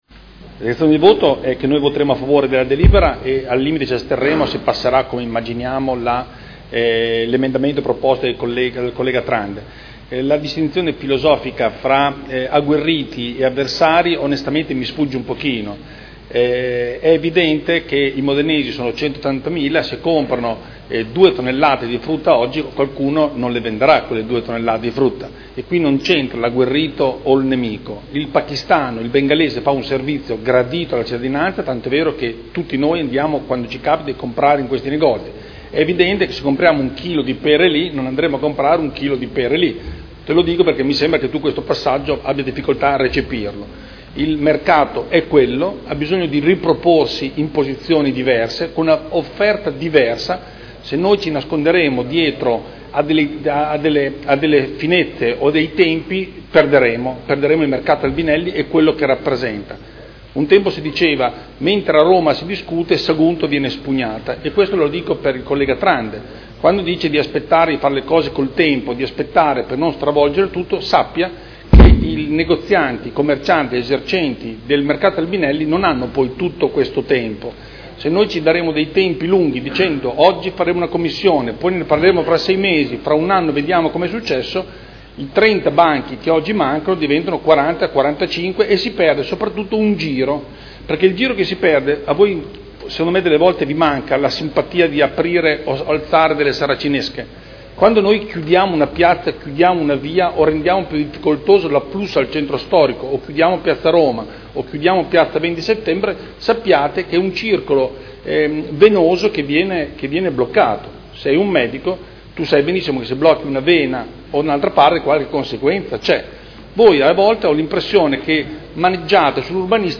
Seduta del 3 marzo. Proposta di deliberazione: Regolamento Comunale del Mercato coperto quotidiano di generi alimentari denominato “Mercato Albinelli”, ai sensi dell’art. 27, primo comma, lettera D. del D.lgs 114/98 – Approvazione modifiche. Dichiarazione di voto